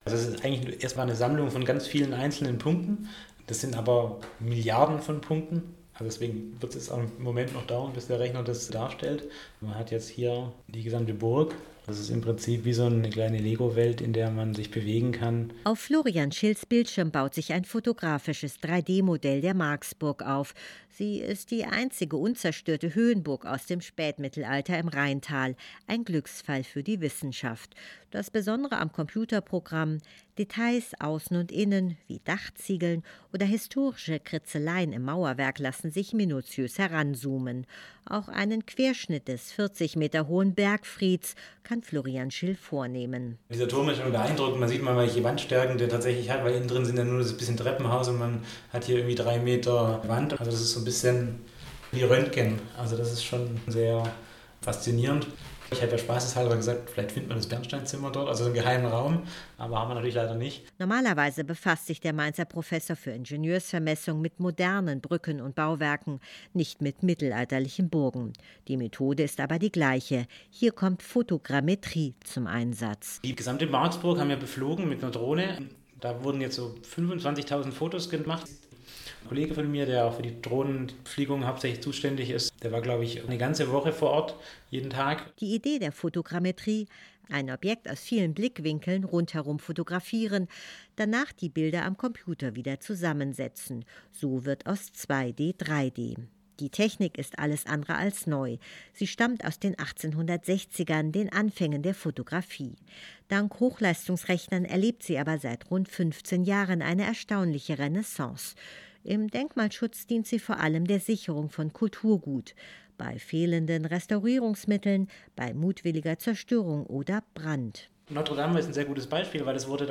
Weiterführende Links Umweltbehörde in Neustadt feiert Festakt und KuLaDig-Netzwerktreffen auf dem Hambacher Schloss Download Digitalisierung der Marksburg, Interview